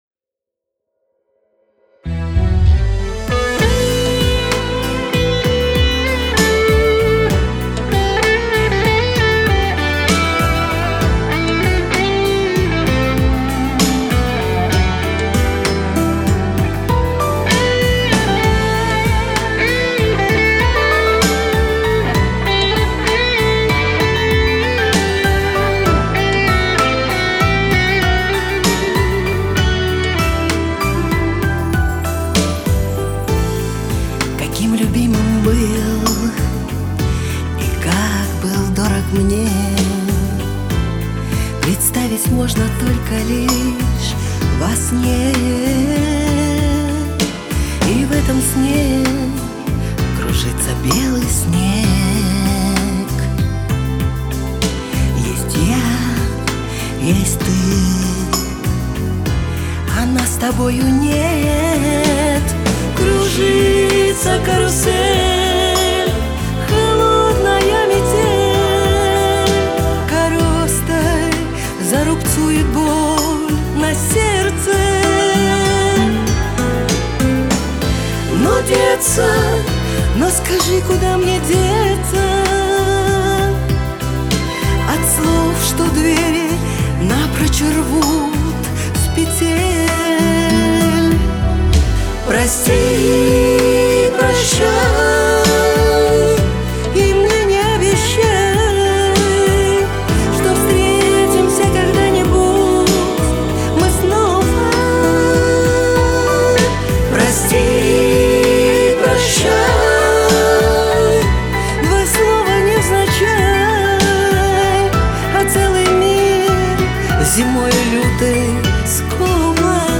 вокал
гитары, бэк-вокал